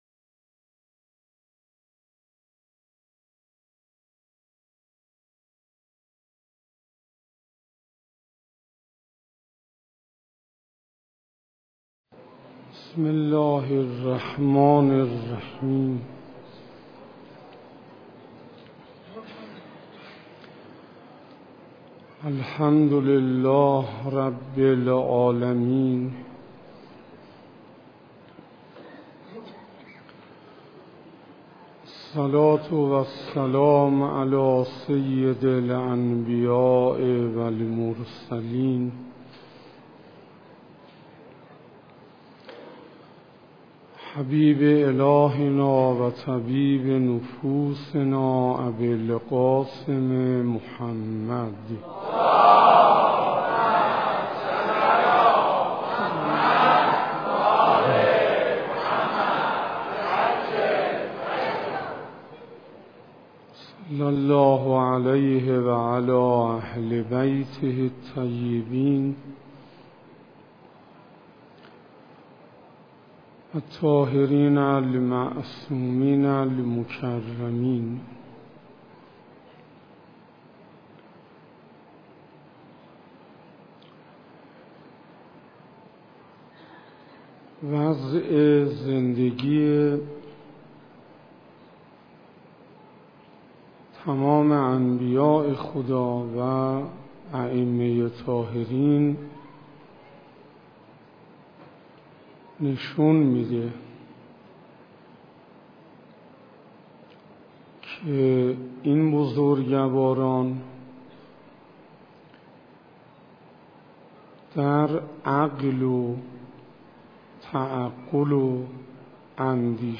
سخنرانی حجت الاسلام انصاریان